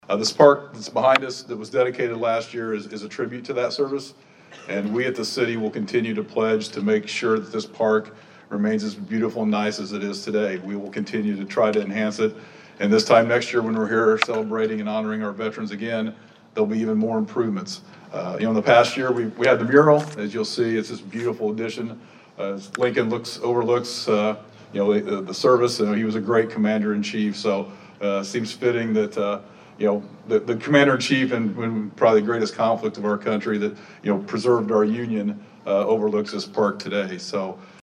It was the First Anniversary of the downtown Vandalia Veteran’s Memorial Park, and it was well represented with a ceremony and then a Veteran’s Day parade through downtown Vandalia on Saturday.
Vandalia Mayor Doug Knebel talked about the Veteran’s Memorial Park and that continued improvements will be happening there.